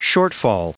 Prononciation du mot shortfall en anglais (fichier audio)